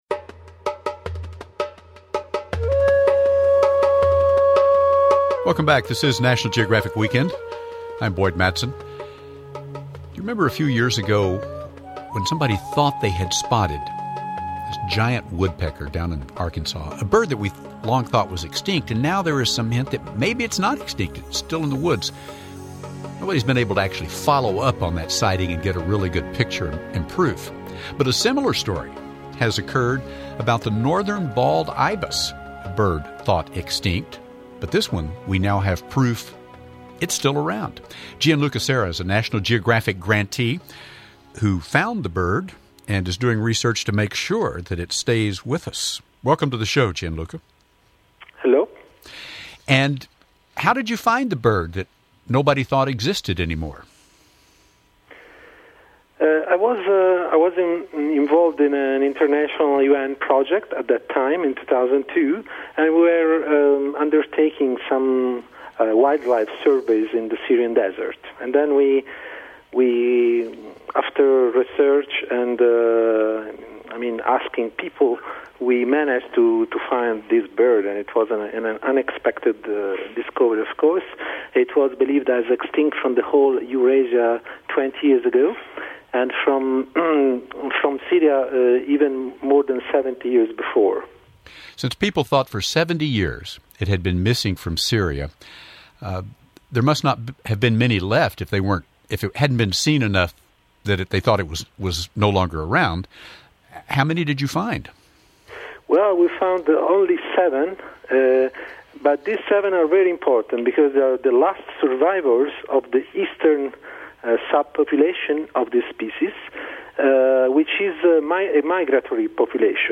Documentaries and Radio Interviews | THE LAST FLIGHT OF THE ANCIENT GUIDE OF HAJJ